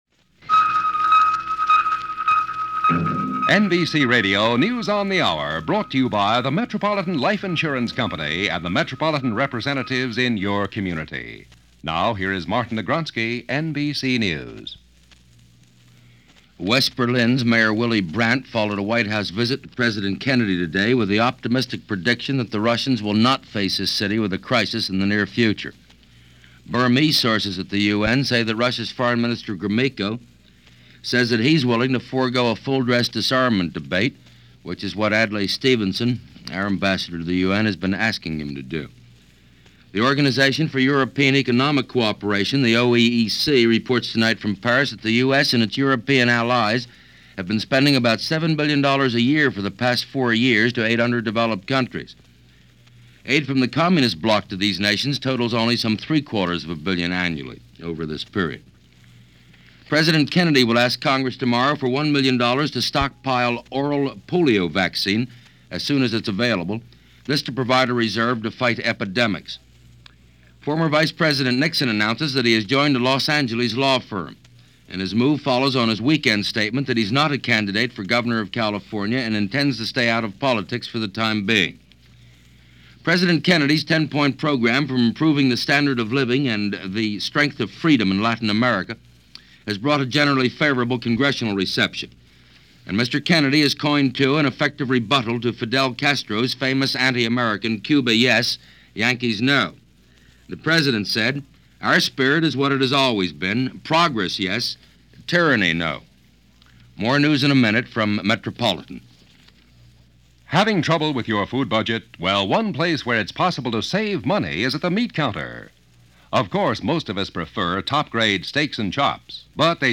All that, and a lot more for this March 13, 1961 as reported by Martin Agronsky and the NBC Hourly News.